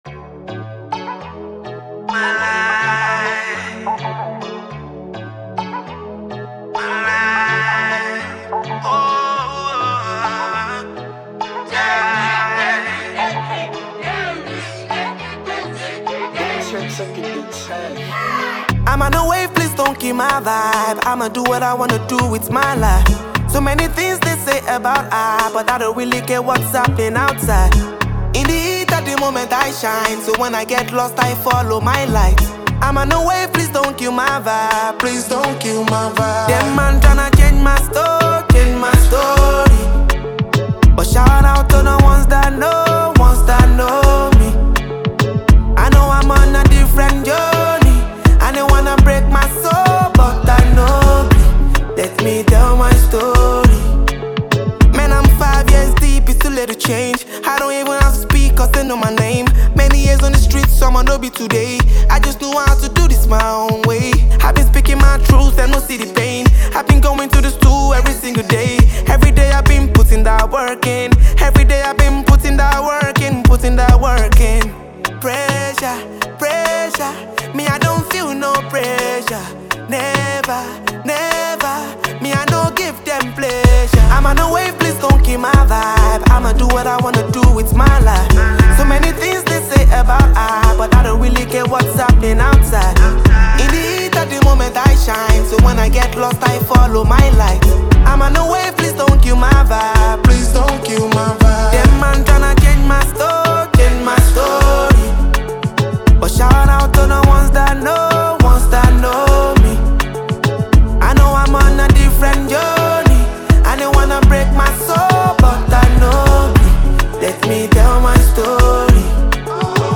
Versatile Nigerian singer and songwriter
the acclaimed American production duo
Afrobeat